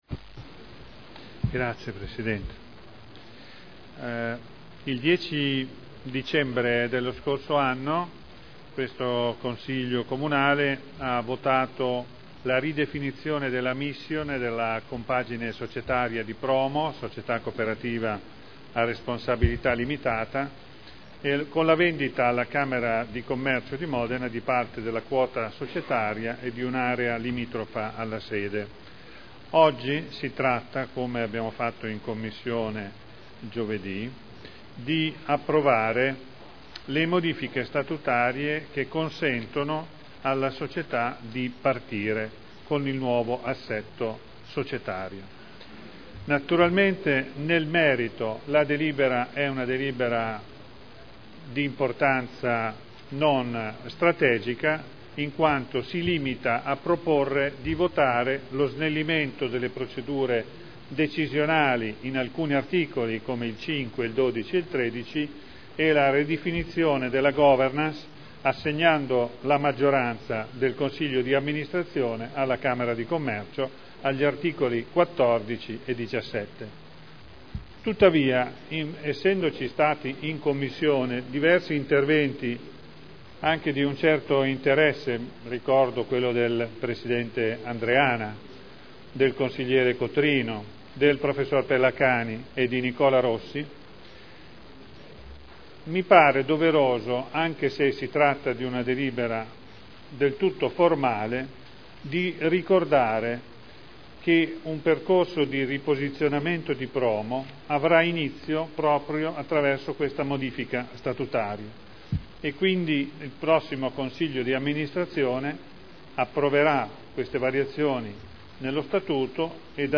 Graziano Pini — Sito Audio Consiglio Comunale